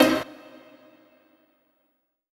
HOUSE130.wav